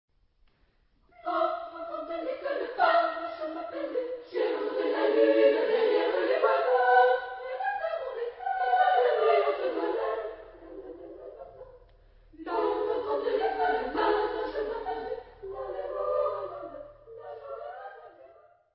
Type de choeur : SSA  (3 voix égale(s) d'enfants )
Tonalité : mi majeur